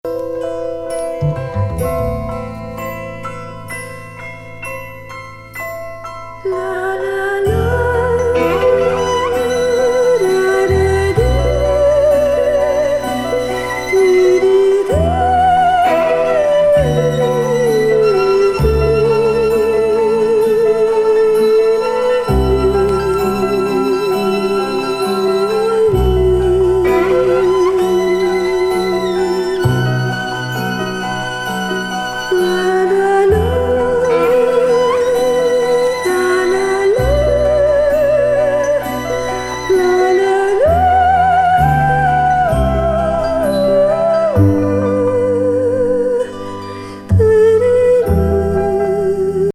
ムード・オーケストラ・＋ド・サイケ歌!